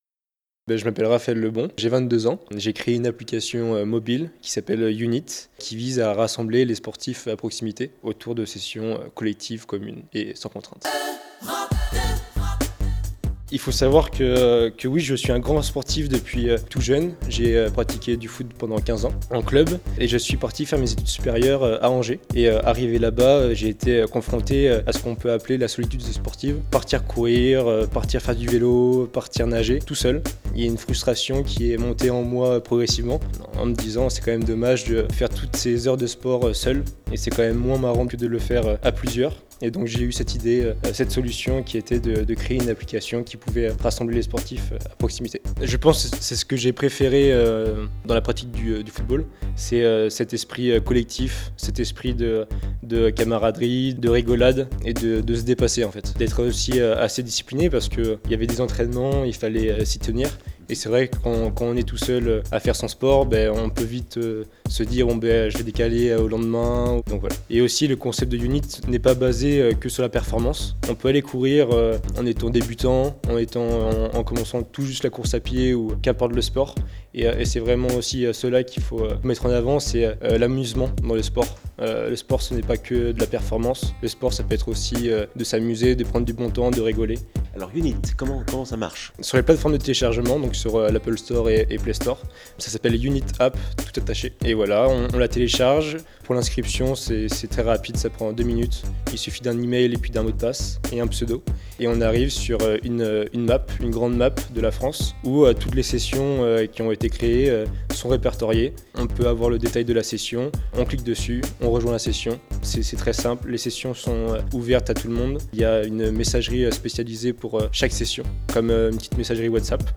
au micro EUROPE 2